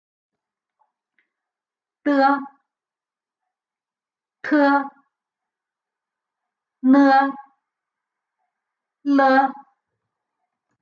Uitspraak van medeklinkers:
d t n l (audio)
d-t-n-l-audio-2.mp3